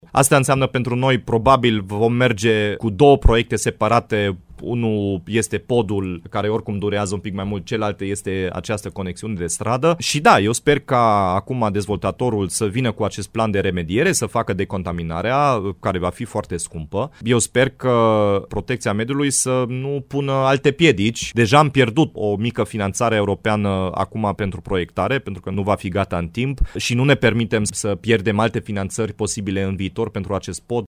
Primarul Dominic Fritz atrage atenția că municipalitatea nu va primi terenul necesar pentru realizarea conexiunii până când dezvoltatorul nu va fi sigur că își poate continua proiectul.
Dominic-Fritz-Solventul-1.mp3